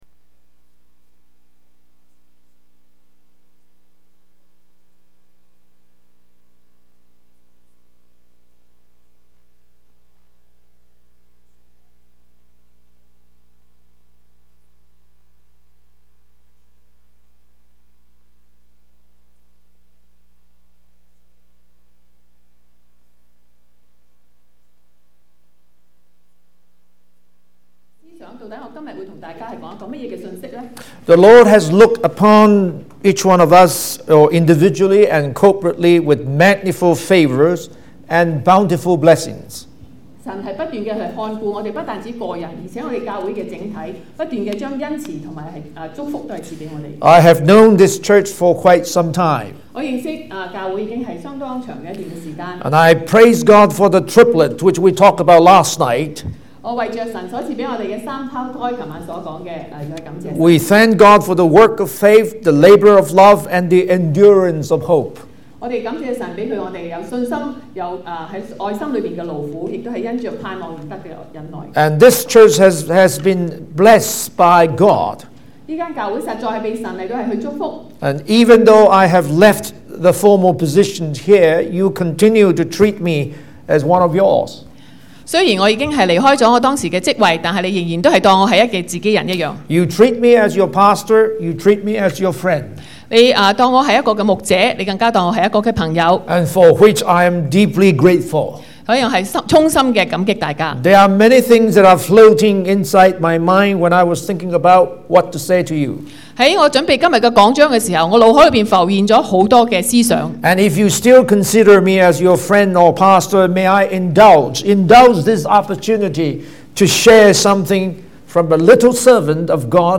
英語講道 - 粵語傳譯